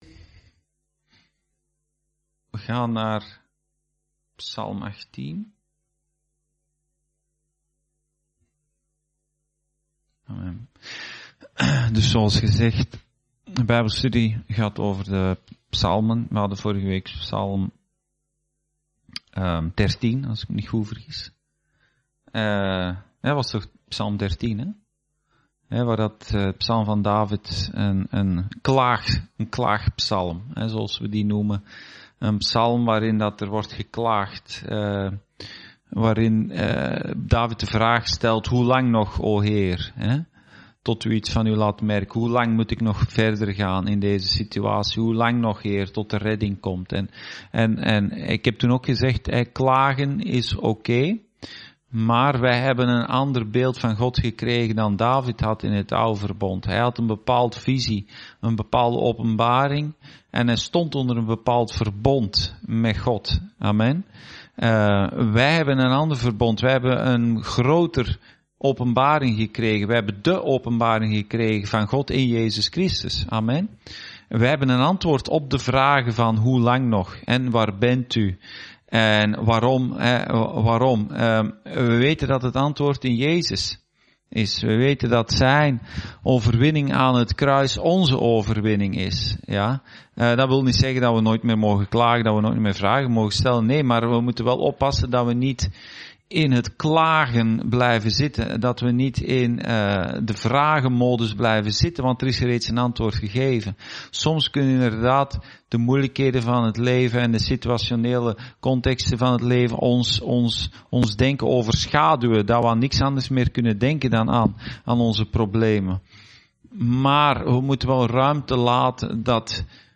Bijbelstudie: Psalm 18: trouwheid van God